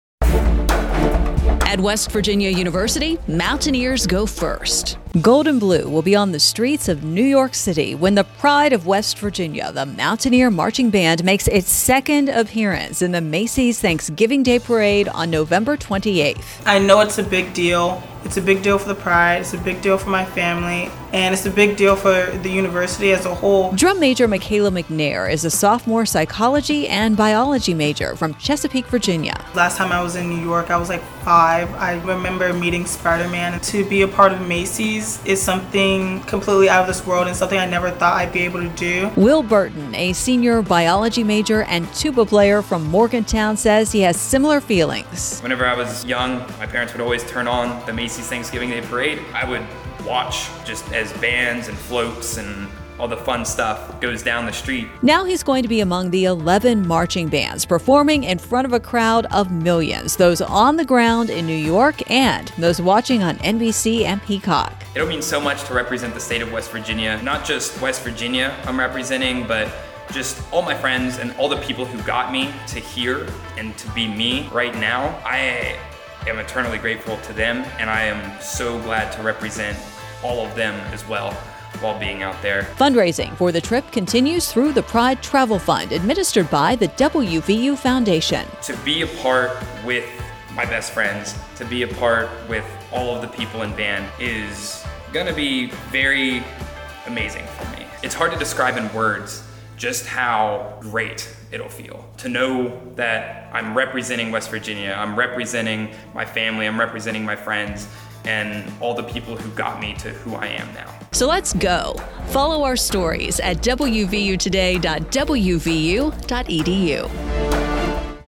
WVU Pride Macy's Parade radio spot